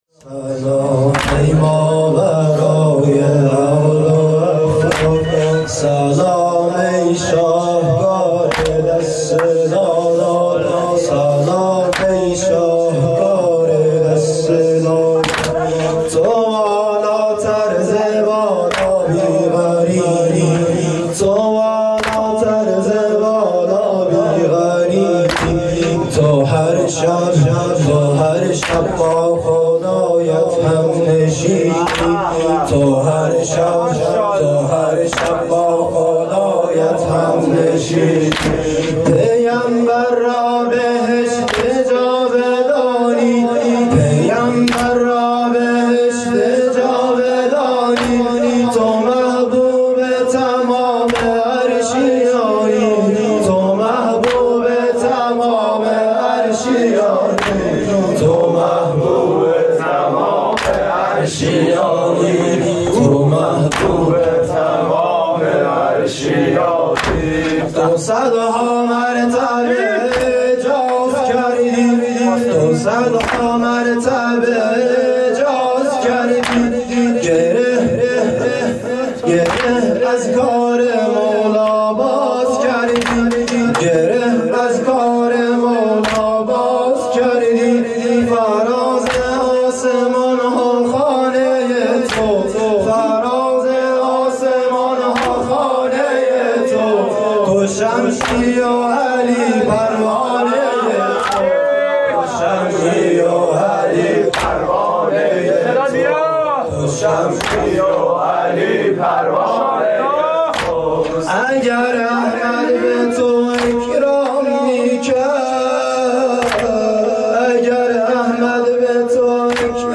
حسینیه
مداحی فاطمیه